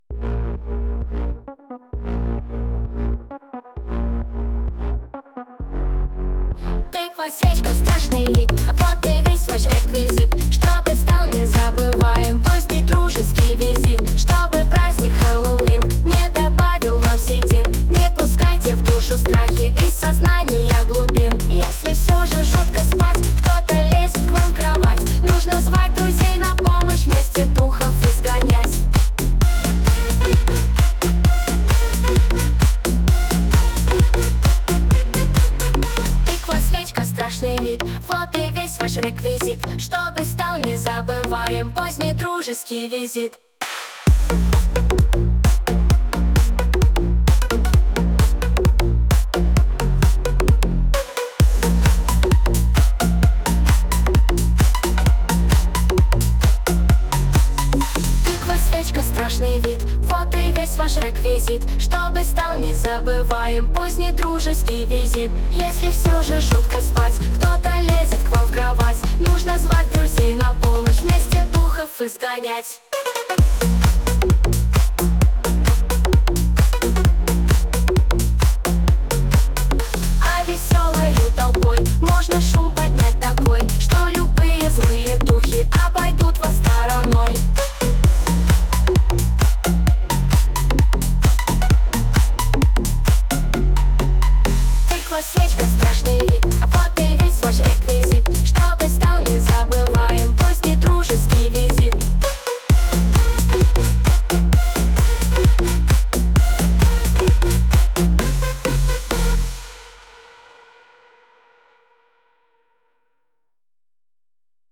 Жанр: Ambient Techno